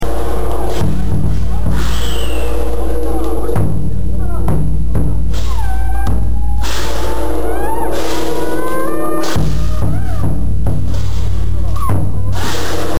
ラッパや太鼓（龍囃子）などに加えて盛り上げるのが、爆竹なのだ。
6月の小屋入りからはじまる稽古も、8月のお盆過ぎからピークとなり、毎夜、踊町近辺では龍囃子が聞こえてくる。